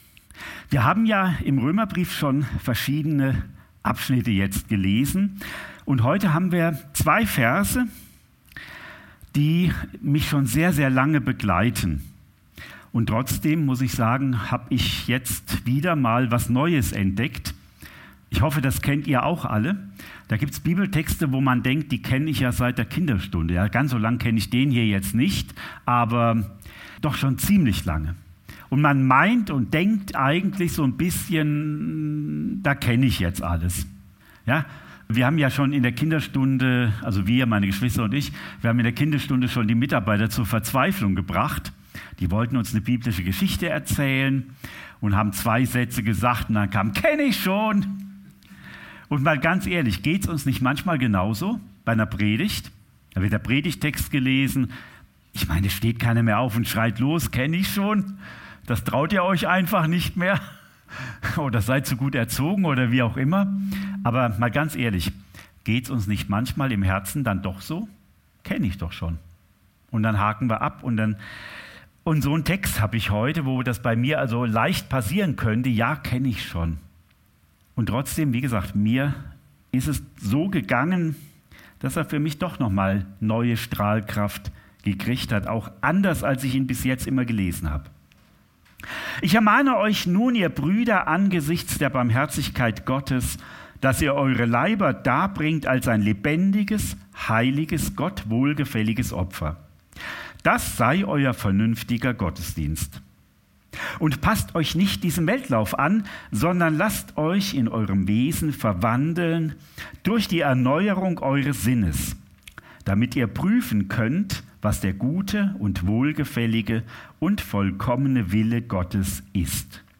Predigt vom 07. Juli 2024 – Süddeutsche Gemeinschaft Künzelsau